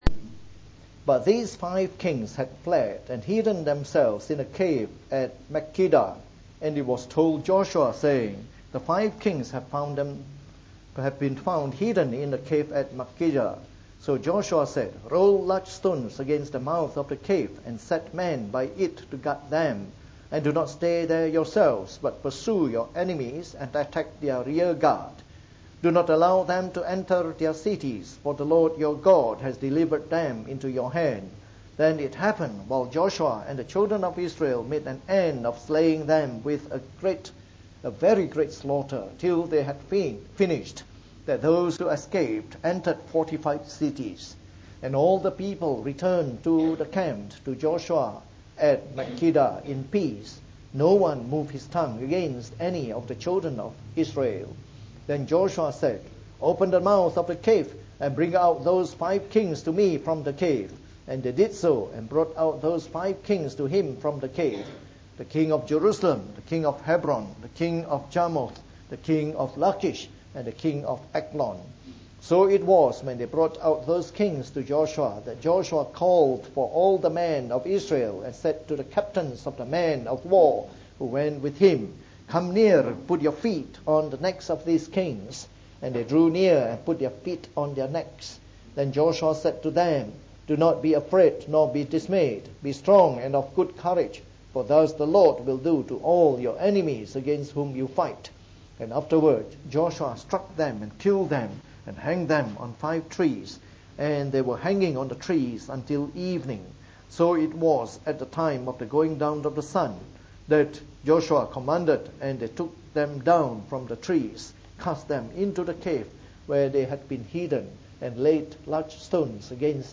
Preached on the 19th of October 2014. From our series on the Book of Joshua delivered in the Morning Service.